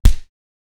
hurt.wav